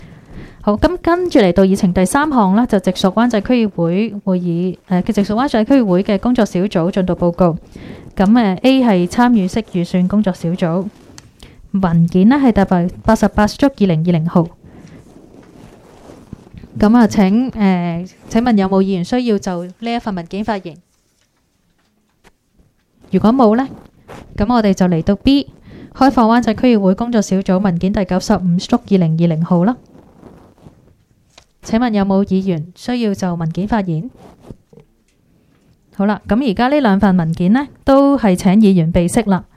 区议会大会的录音记录
湾仔民政事务处区议会会议室